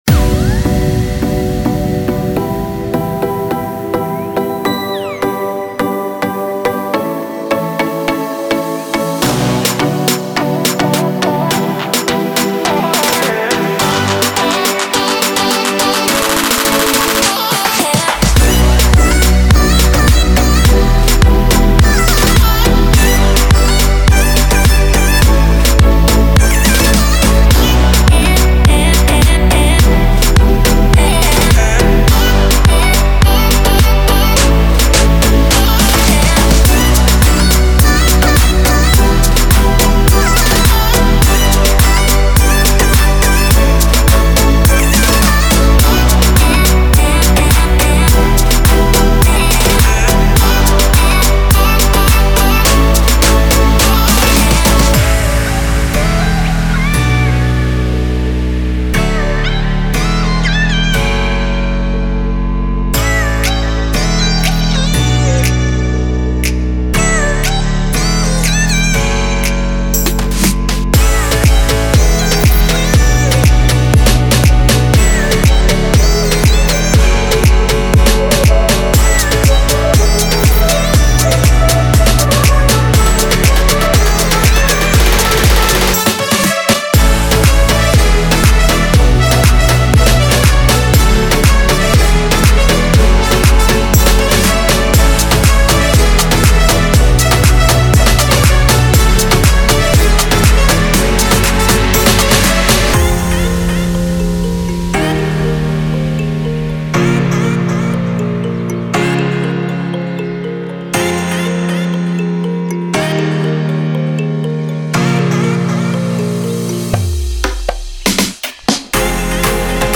希望有很棒的贝斯音色，丰富的鼓声和循环声，MIDI文件，合成器打击乐，令人难忘的人声循环，
大量的鼓声单拍和5个完整的制作套件。